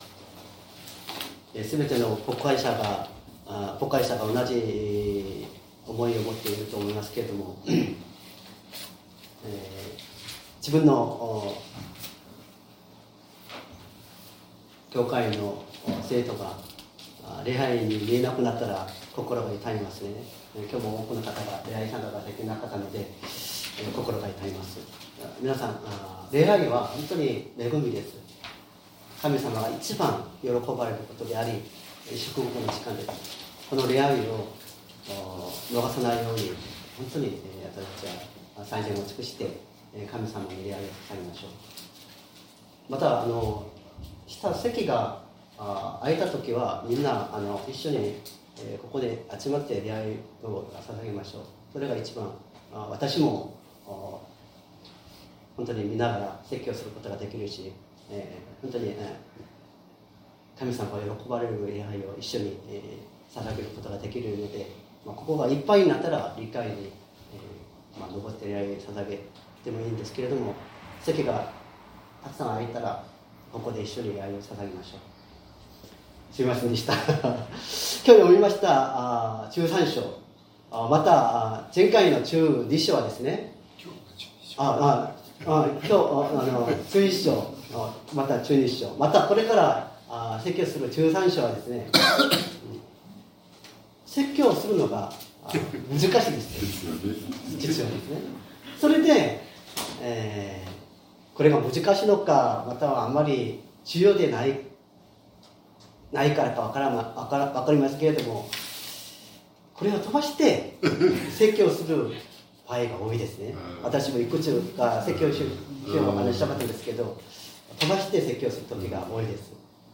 2024年11月10日朝の礼拝「過ぎし日を顧みましょう」善通寺教会
音声ファイル 礼拝説教を録音した音声ファイルを公開しています。